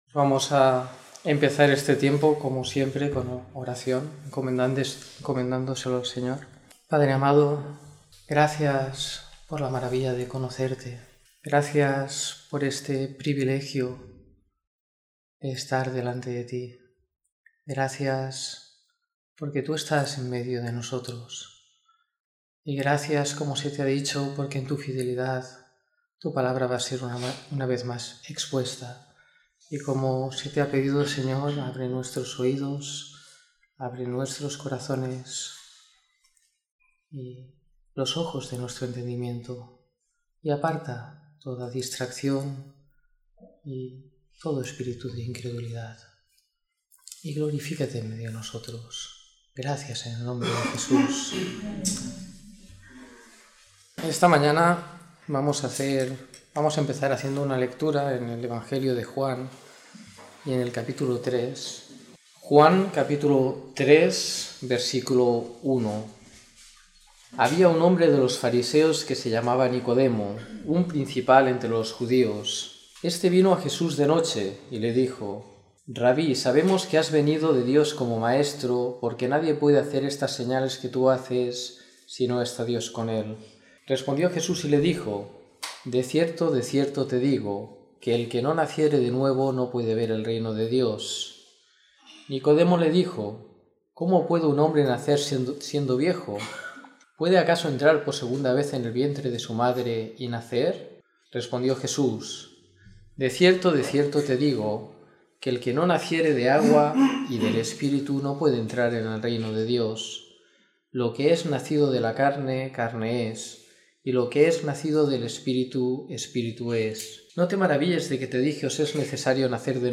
Domingo por la Mañana